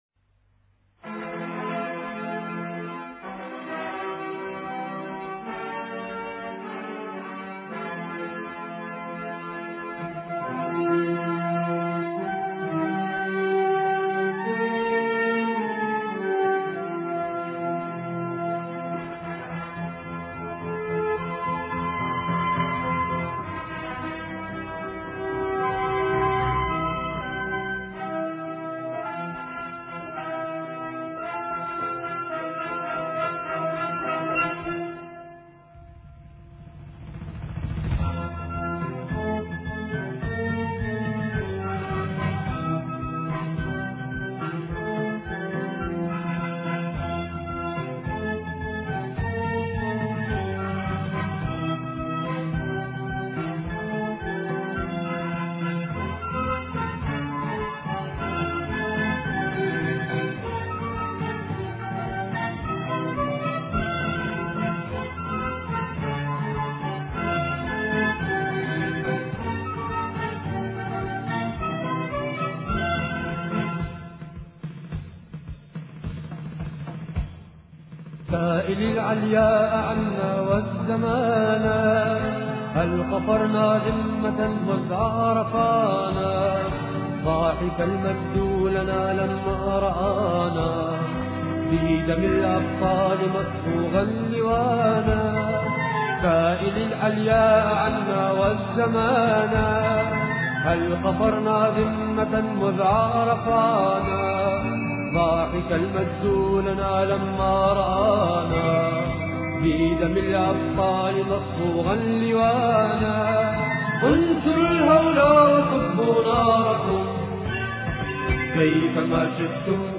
ثورة فلسطين الإثنين 23 يونيو 2008 - 00:00 بتوقيت طهران تنزيل الحماسية شاركوا هذا الخبر مع أصدقائكم ذات صلة الاقصى شد الرحلة أيها السائل عني من أنا..